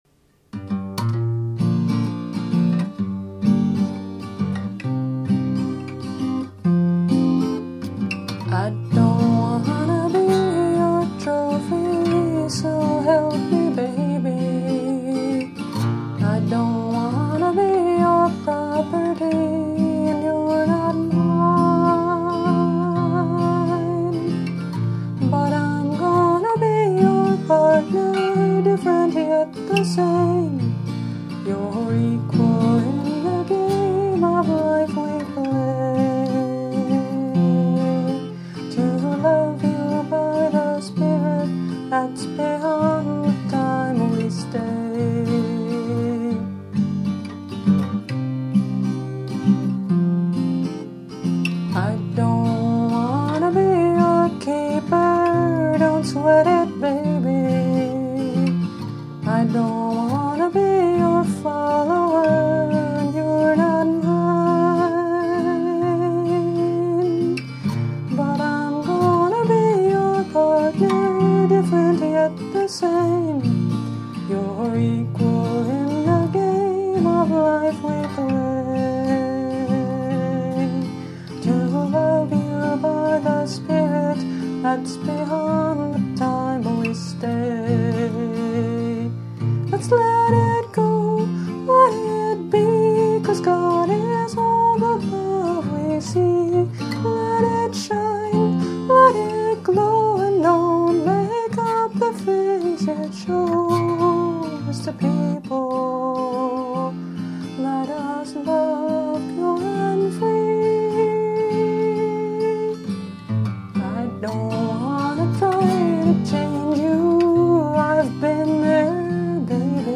Instrument: Tempo – Seagull Excursion Folk Acoustic Guitar
(Capo 3)